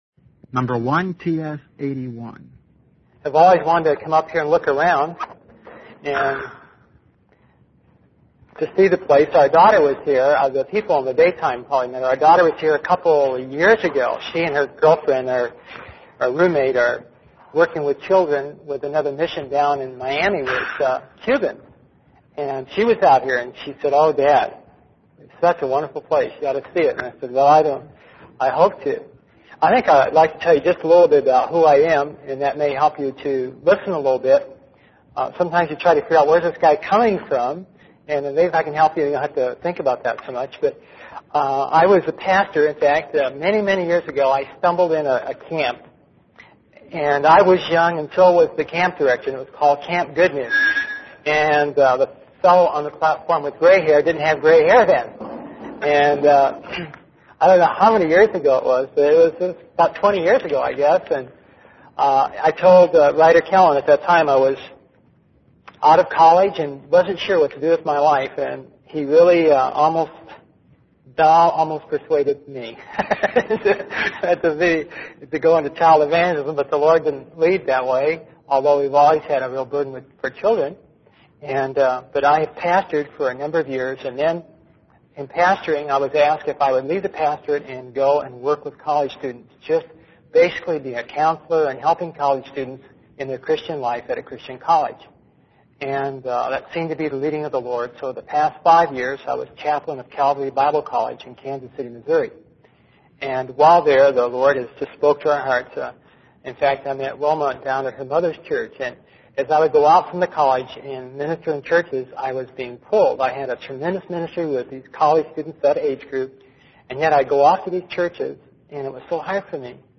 In this sermon, the speaker addresses the issue of receiving criticism as a leader. They emphasize the importance of not having a critical spirit and being open to feedback.